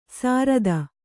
♪ sārada